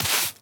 sweeping_broom_leaves_stones_14.wav